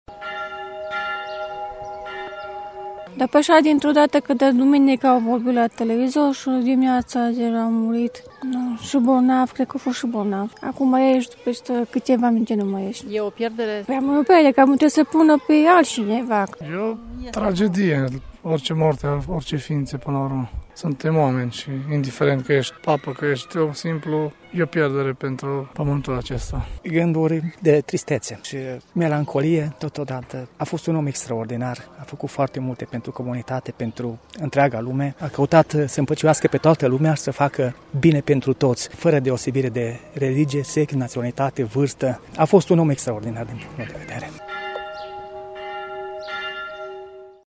Și creștinii din Târgu Mureș deplâng moartea papei Francisc: